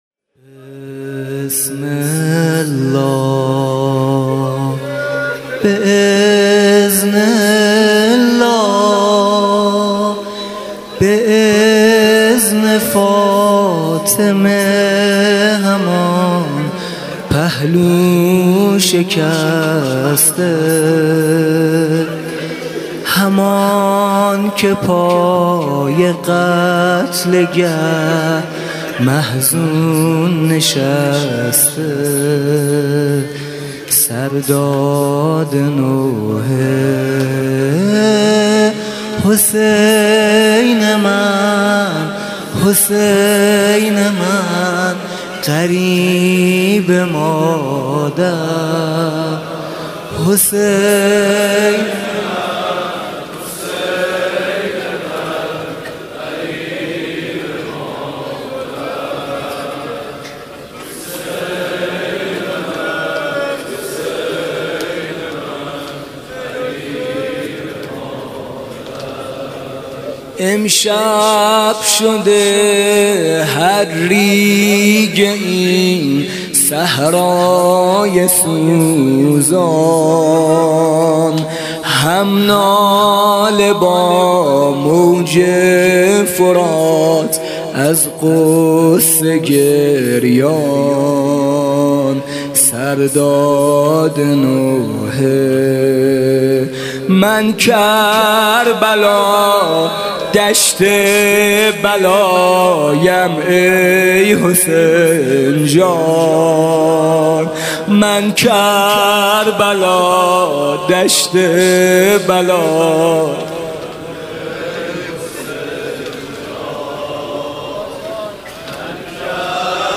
شب دوم محرم ۱۳۹۶